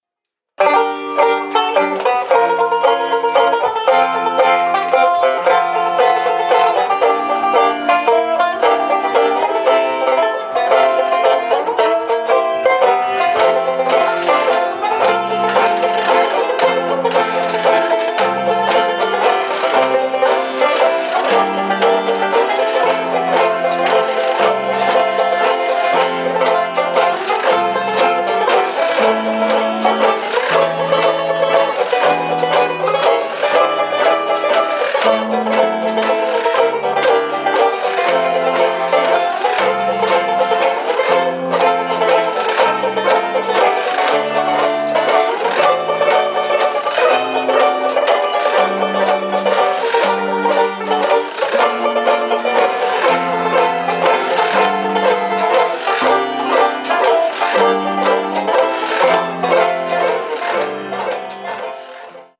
This song is in 3/4 waltz time.
Listen to the WineLand Banjo Band perform "Always" (mp3)